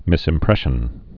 (mĭsĭm-prĕshən)